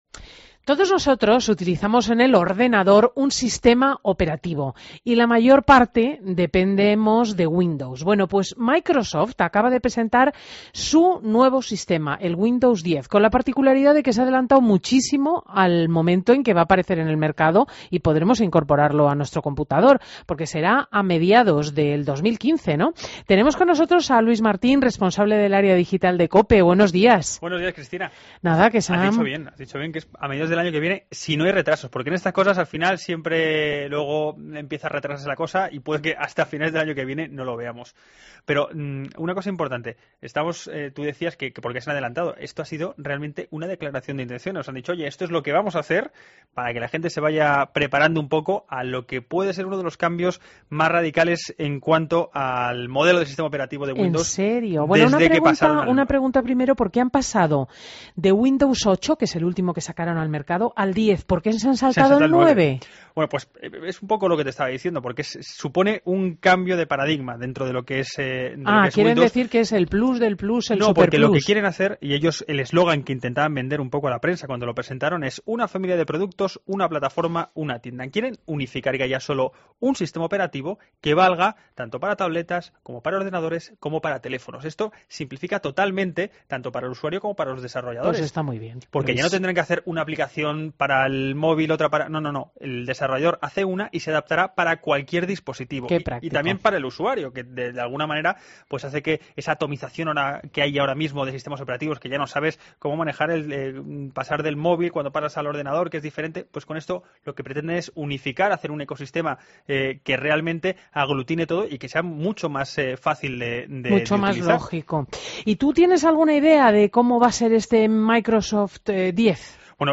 Entrevistas